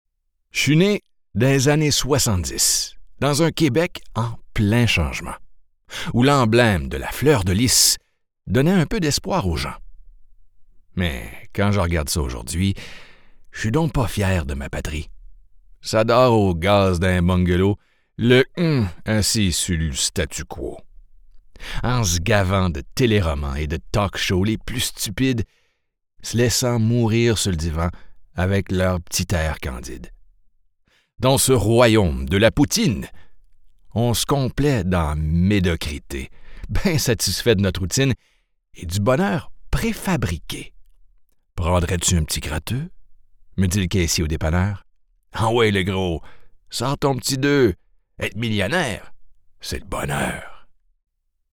Male
30s, 40s
French Canadian (Native) , American English , Canadian English , French
Assured, Authoritative, Character, Cheeky, Confident, Cool, Corporate, Deep, Engaging, Friendly, Gravitas, Natural, Reassuring, Sarcastic, Smooth, Soft, Wacky, Warm, Witty, Versatile
Voice reels
Microphone: Rode NT1a